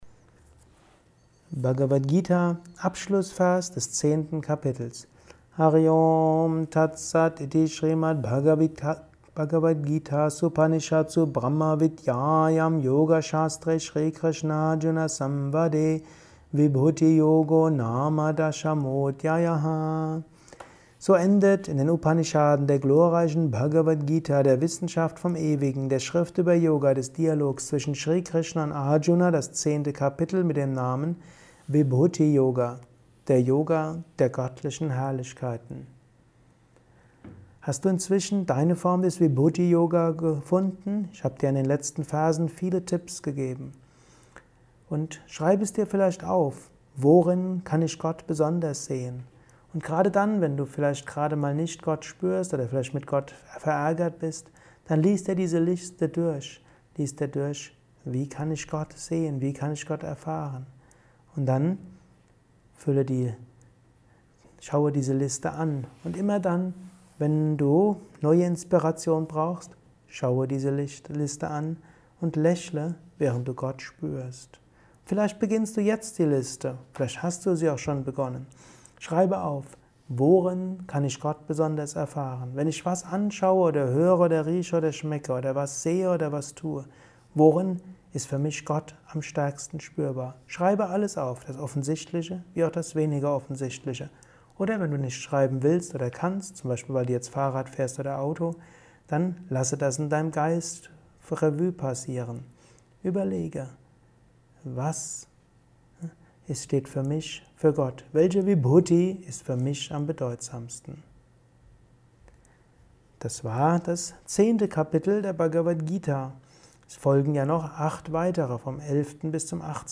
Gott ist erfahrbar. Dies ist ein kurzer Kommentar als Inspiration für den heutigen Tag